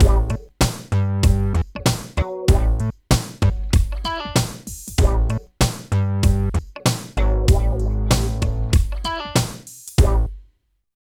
28 LOOP   -R.wav